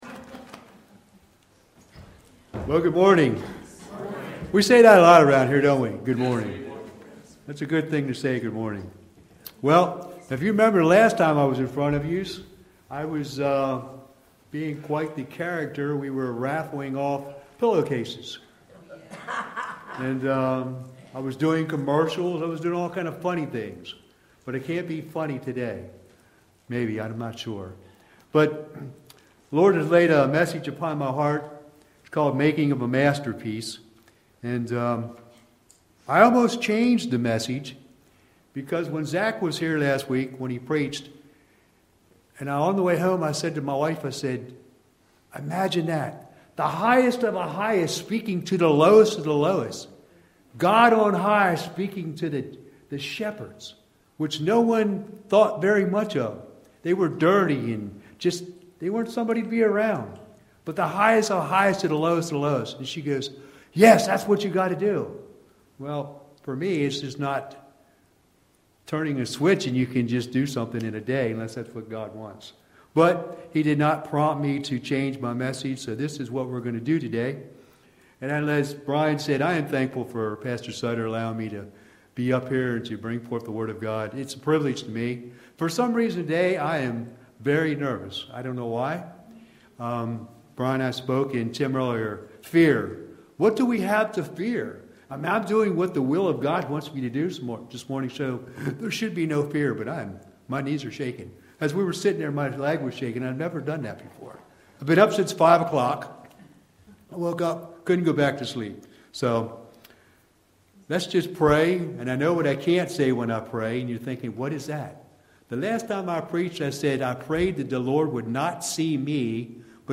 YBC Worship Service – 12/28/2025
Watch Online Service recorded at 9:45 Sunday morning.